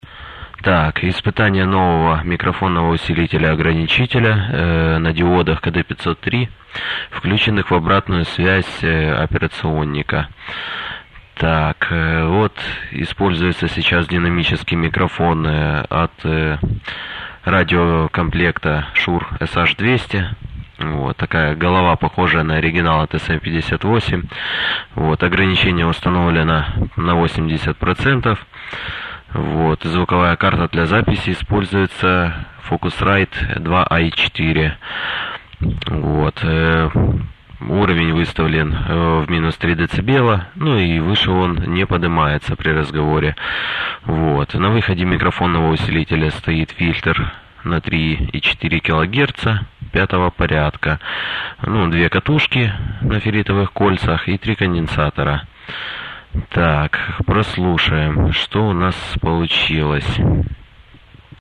Вот что получилось, к сожалению не в эфире.
micro.mp3